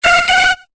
Cri de Natu dans Pokémon Épée et Bouclier.